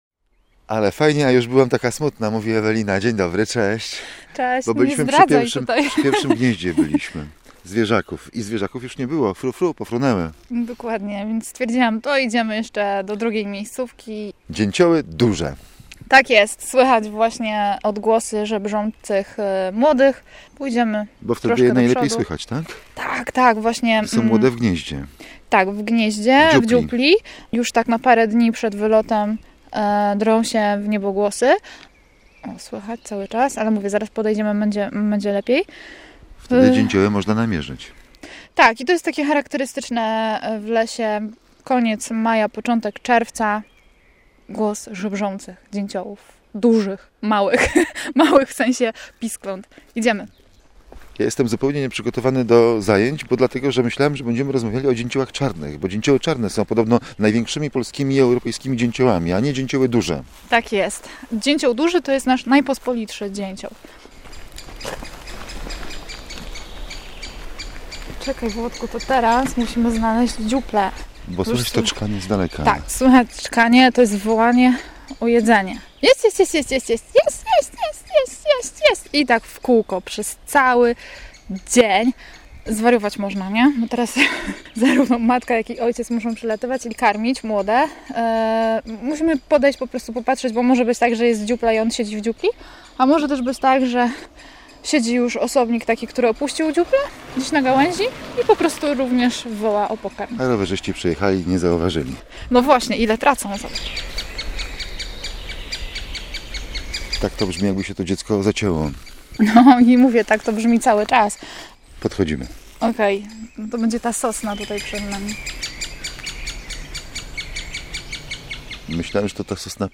Dzięcioły najłatwiej można odszukać na przełomie maja i czerwca, gdy w dziuplach głośno nawołują pisklęta, jeść, jeść, jeść….
pTAK-dzieciolduzy.mp3